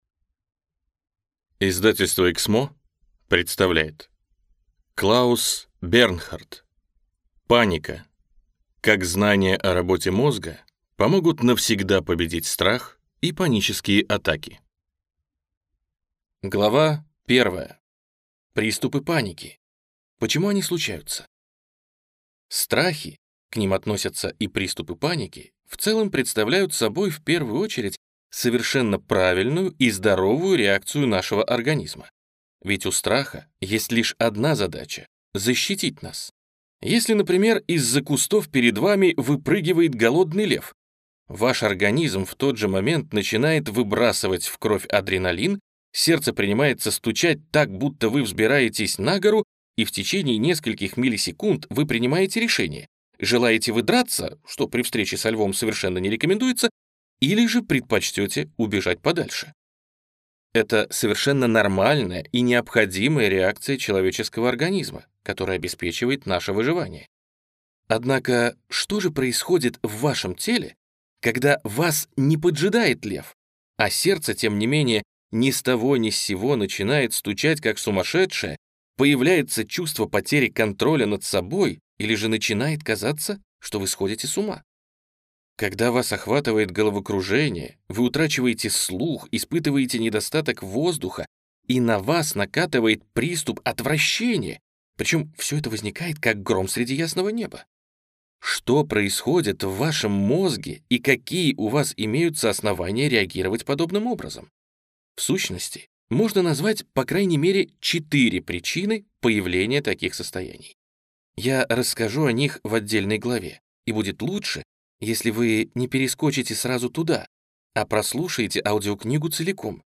Аудиокнига Паника. Как знания о работе мозга помогут навсегда победить страх и панические атаки | Библиотека аудиокниг